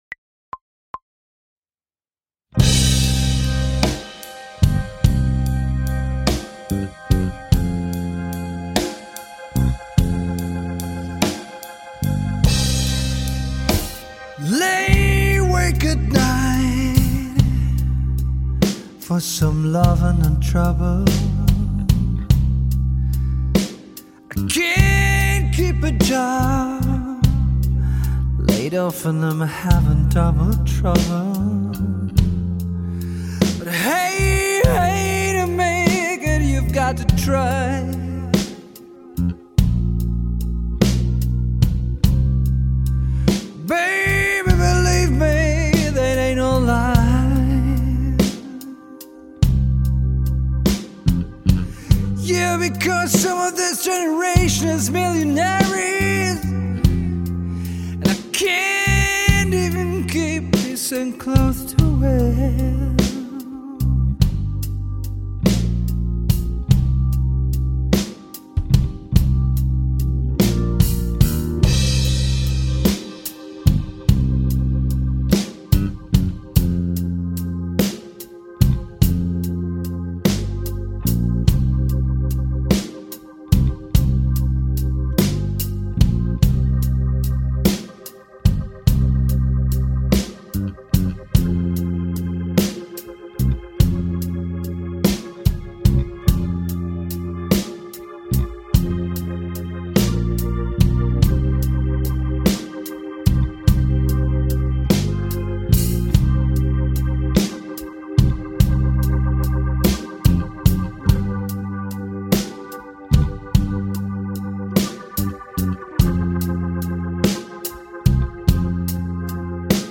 Tausta tällä kertaa laulusolistilla: